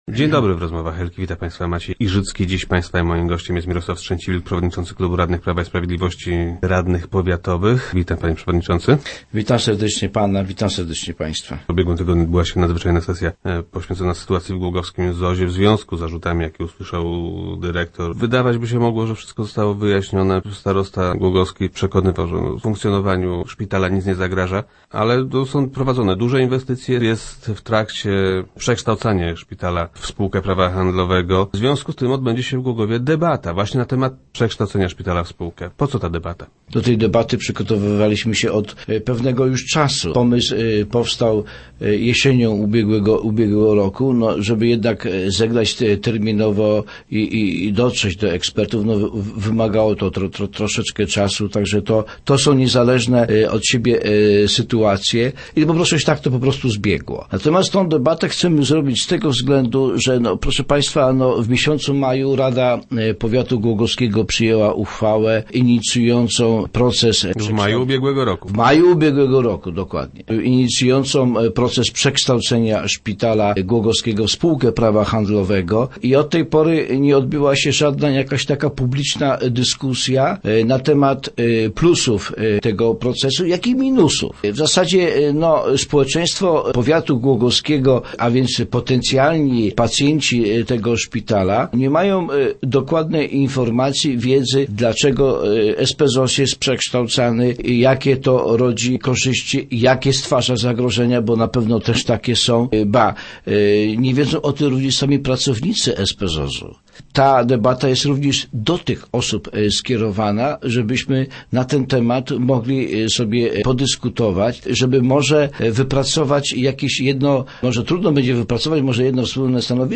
Jej organizatorem są powiatowi radni Prawa i Sprawiedliwości. - Chcemy, żeby mieszkańcy Głogowa i nie tylko, dowiedzieli się o plusach i minusach takiego przedsięwzięcia – mówi Mirosław Strzęciwilk, przewodniczący klubu radnych PiS.